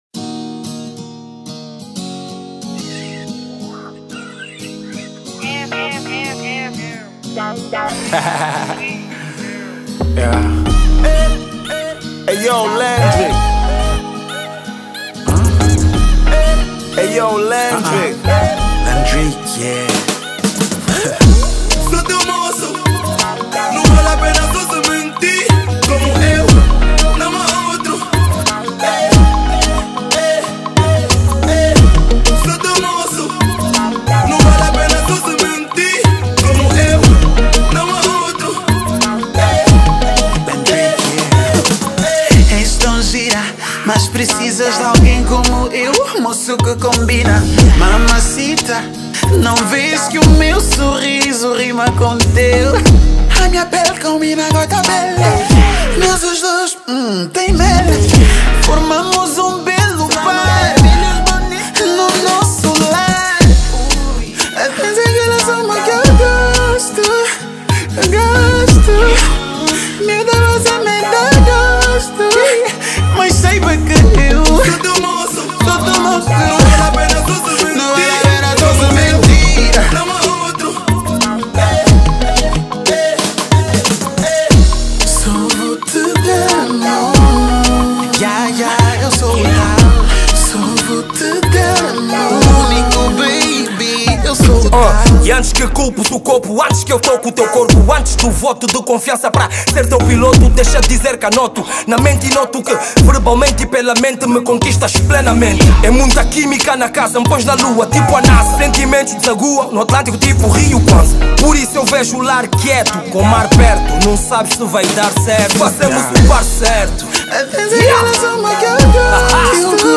Kizomba Marrabenta